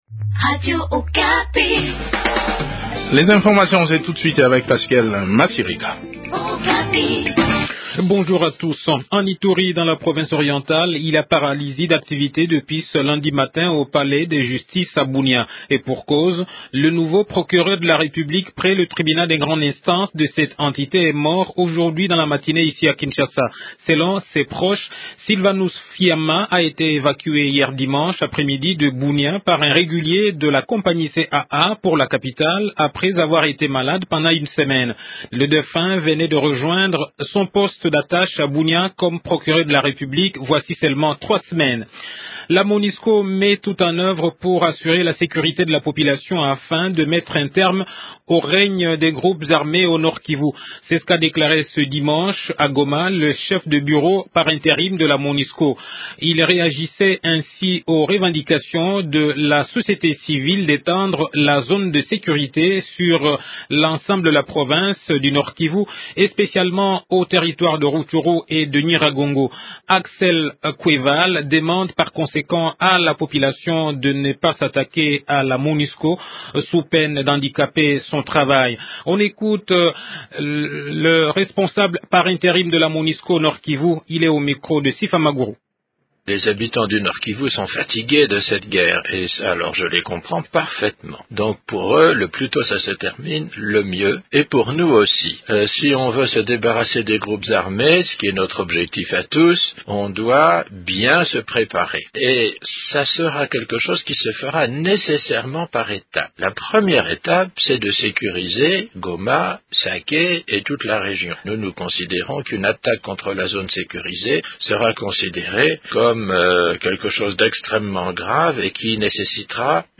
Invite: Maker Mwangu, ministre de l’Enseignement primaire, secondaire et professionnel (EPSP)